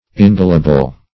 Ingelable \In*gel"a*ble\